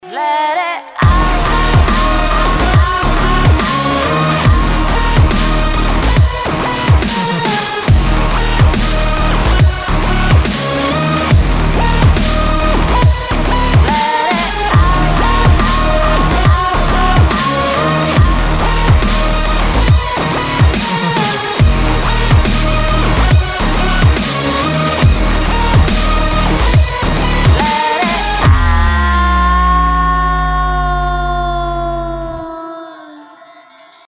• Качество: 320, Stereo
женский вокал
Electronic
Dubstep
Vocal Step